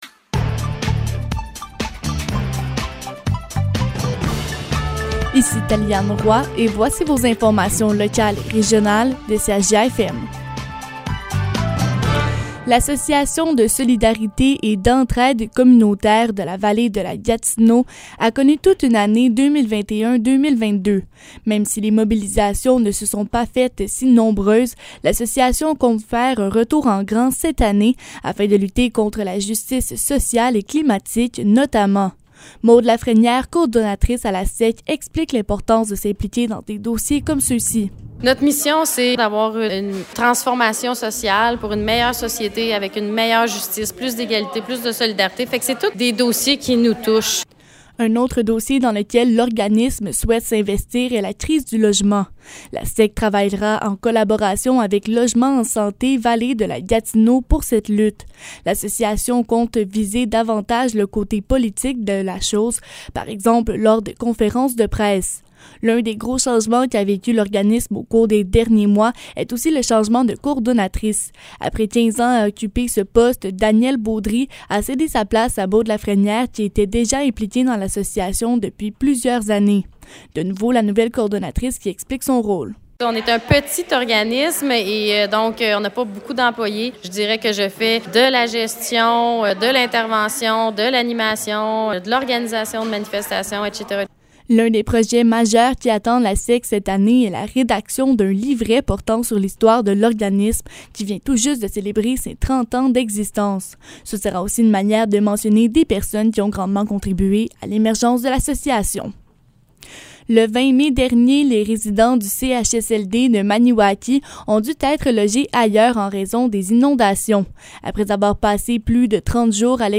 Nouvelles locales - 21 juin 2022 - 15 h